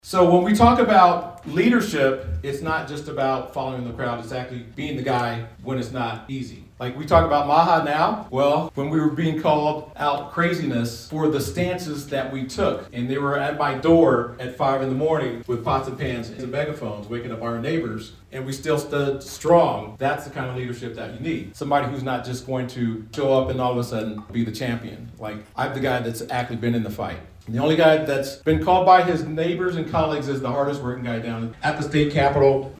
Carroll County Republican Committee Hosts Candidates Running For Governor In GOP Forum Wednesday Evening
Not an empty seat could be found at the Santa Maria Winery, with attendees having a chance to socialize, hear directly from the GOP’s hopefuls, and having small group discussions with the contenders.
gop-forum-3.mp3